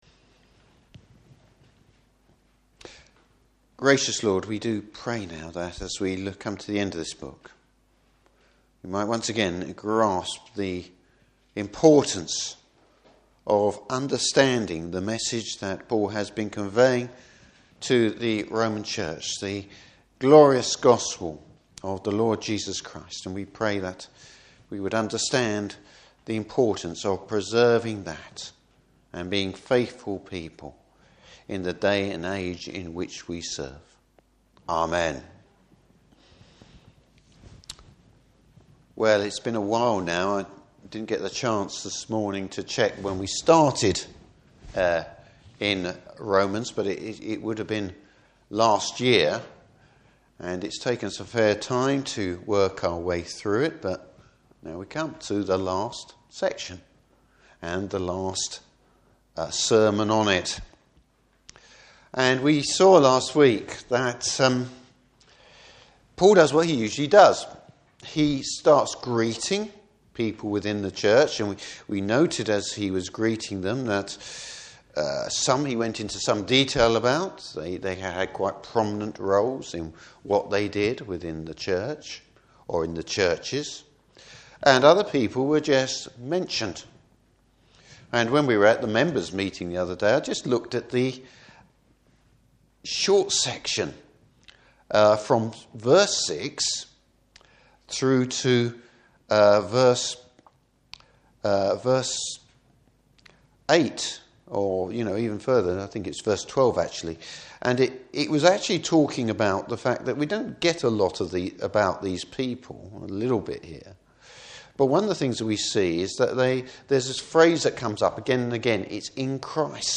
Service Type: Morning Service Paul’s big concern for the Church.